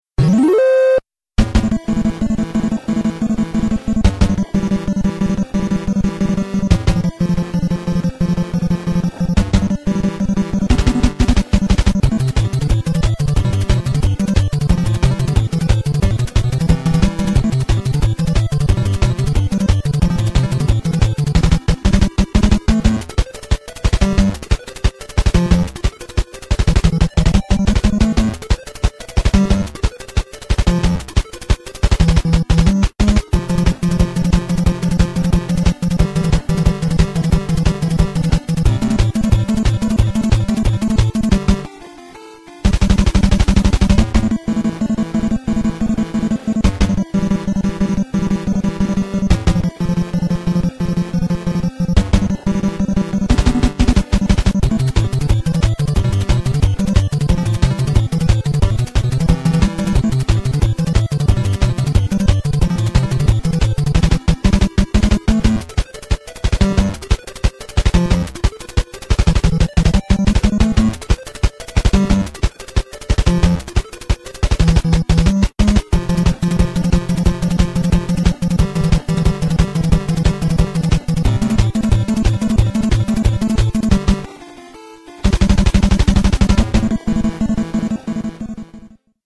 BPM180
Audio QualityPerfect (High Quality)